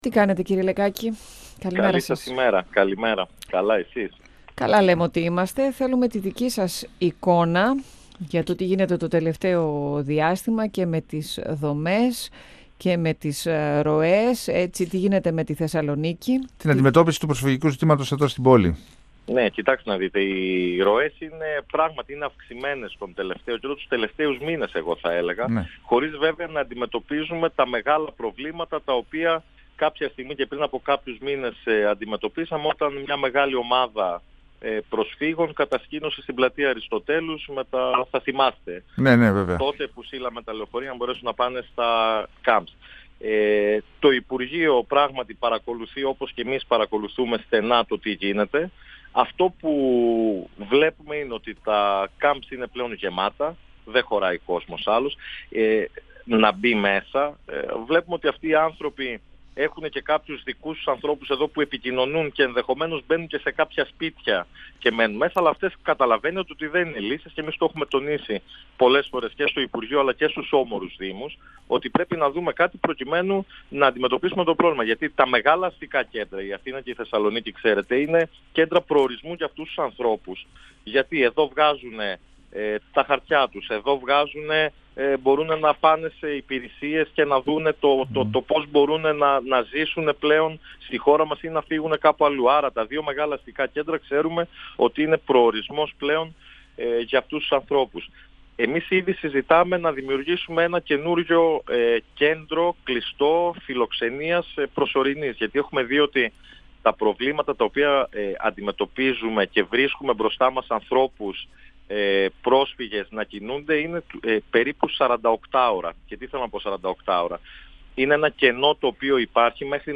Ο αντιδήμαρχος Κοινωνικής Πολιτικής και Αλληλεγγύης του δήμου Θεσσαλονίκης, Πέτρος Λεκάκης στον 102FM του Ρ.Σ.Μ. της ΕΡΤ3